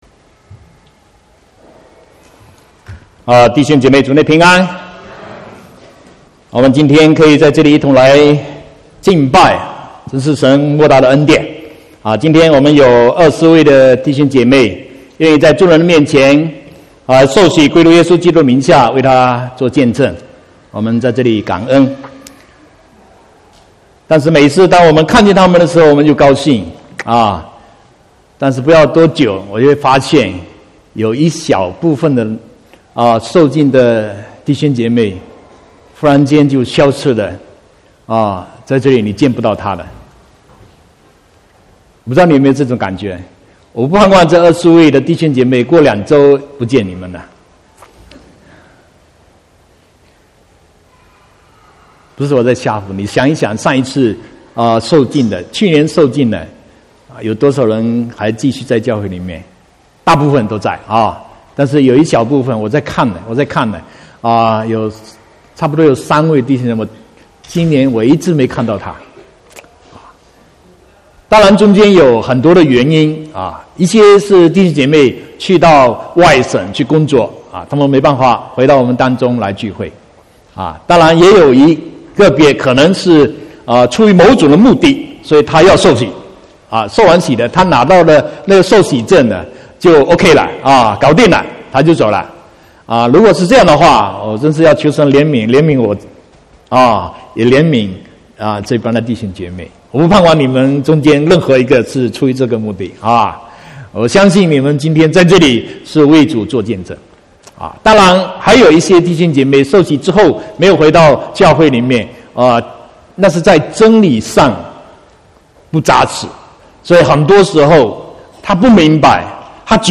23/9/2018 國語堂講道